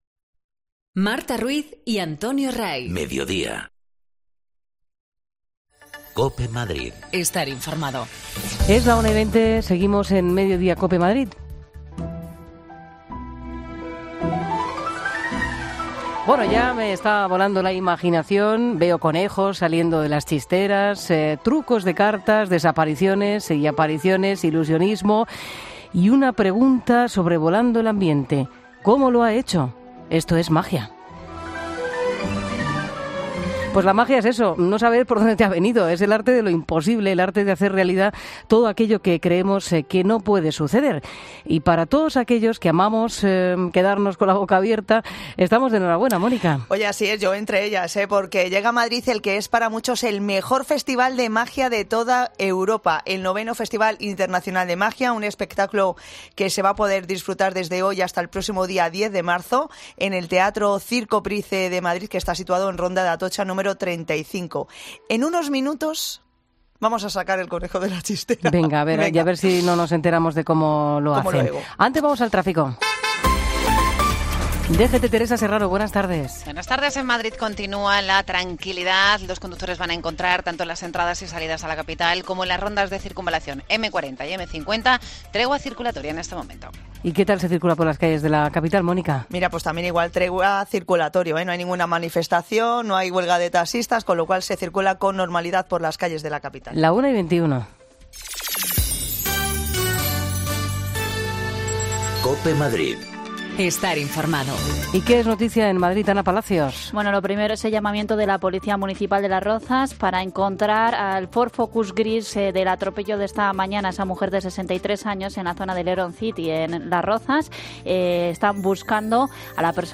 En la Tercera Desconexión Local hemos informado del comienzo del IX Festival Internacional de Magia, un espectaculo que se podrá disfrutar desde este jueves hasta el día 10 de Marzo en el Teatro Circo Price situado en Ronda de Atocha 35. Hemos contado con el famoso ilusionista español Jorge Blass, para conocer más en profundidad este espectáculo.